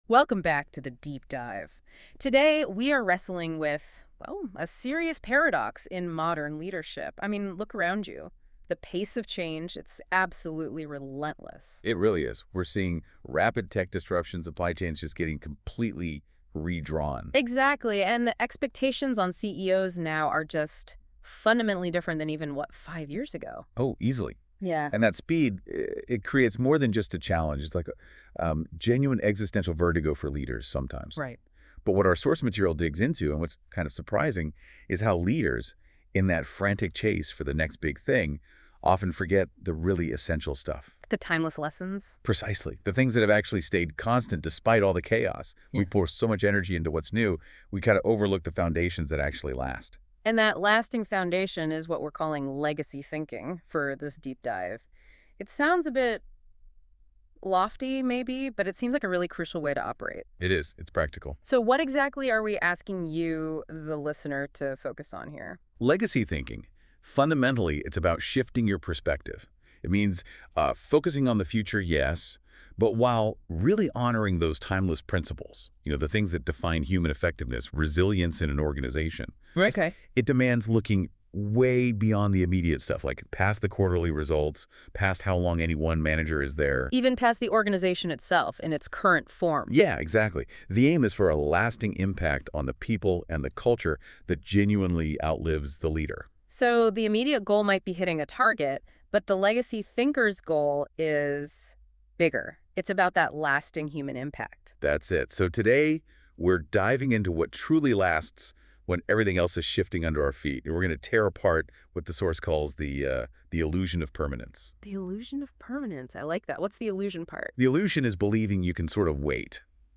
To listen to the Peernovation Deep Dive podcast on the subject, from Google's NotebookLM, join DwAIne & JAIne here: